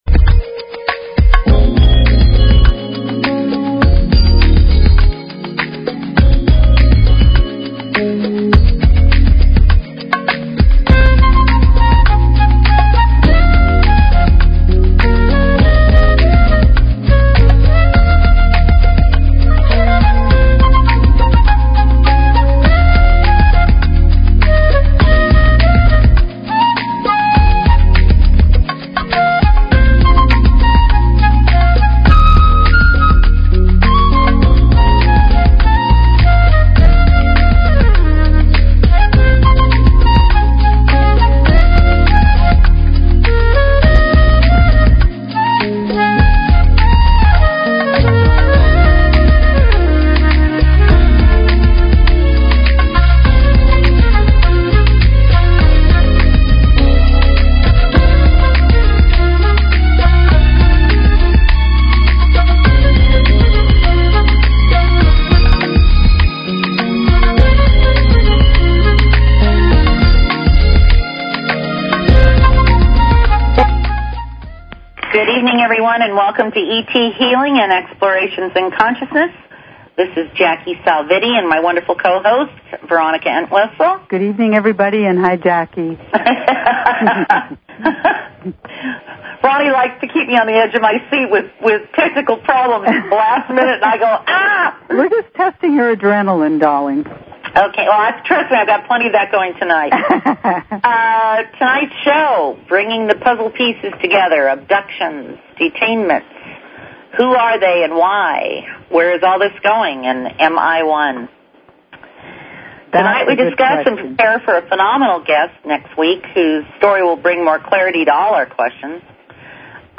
Talk Show ET Healing